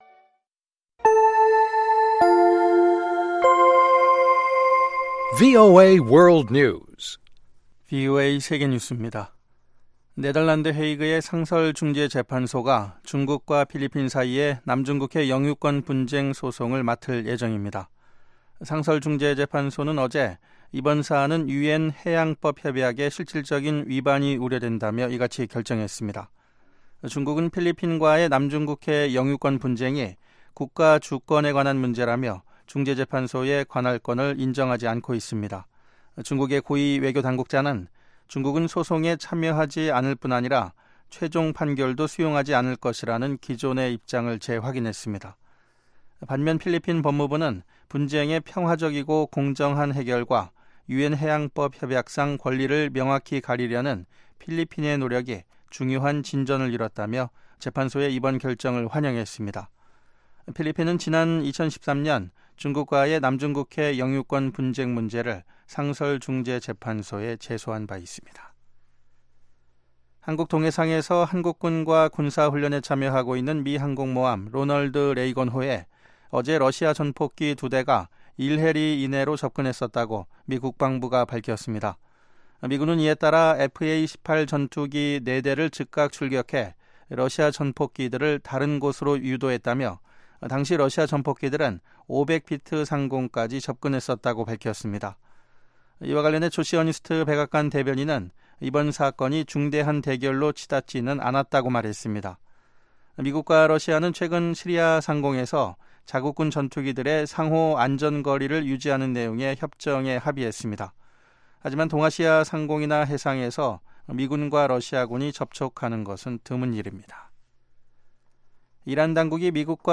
VOA 한국어 방송의 간판 뉴스 프로그램 '뉴스 투데이' 2부입니다. 한반도 시간 매일 오후 9시부터 10시까지 방송됩니다.